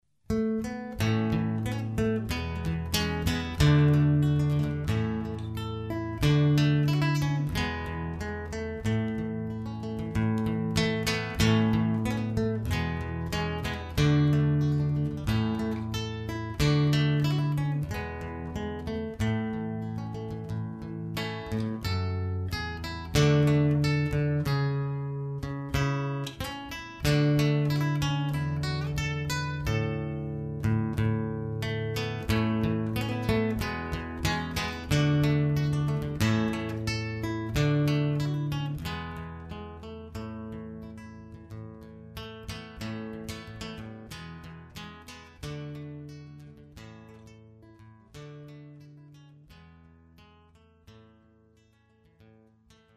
Gitarrist
Sally Gardens (Irish Folk)